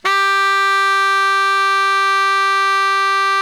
BARI  FF G 3.wav